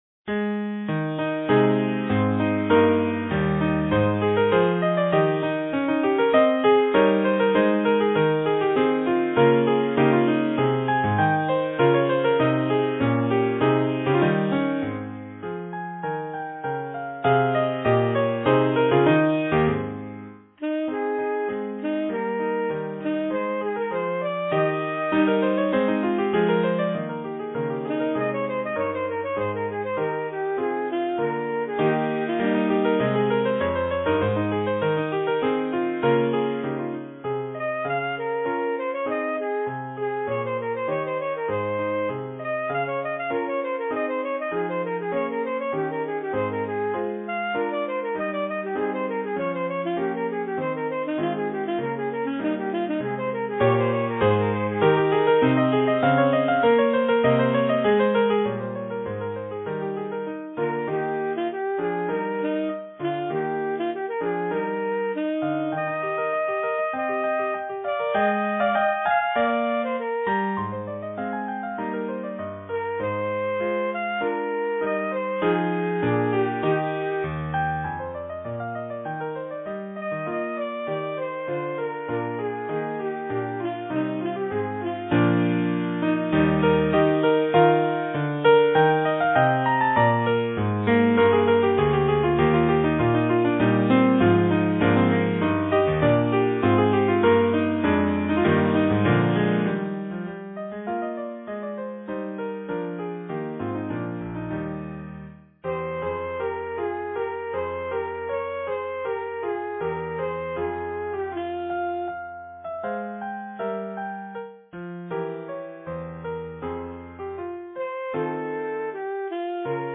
Voicing: Alto Saxophone S